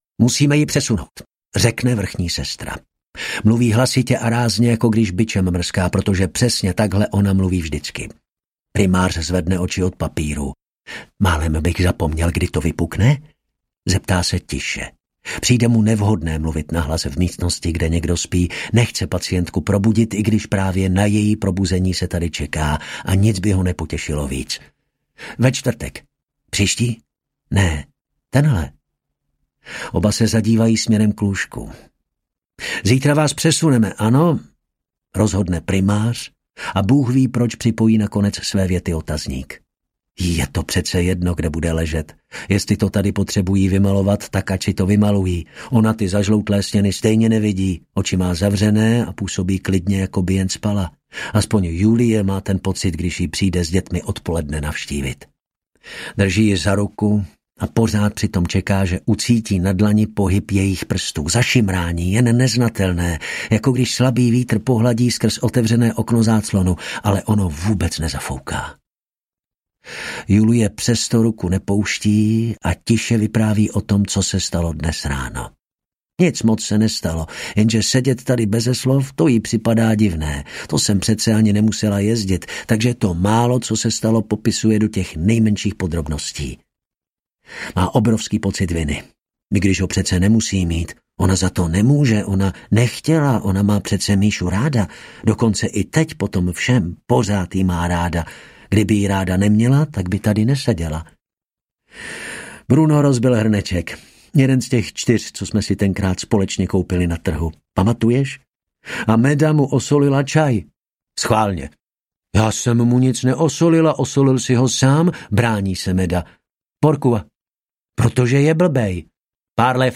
Osm audiokniha
Ukázka z knihy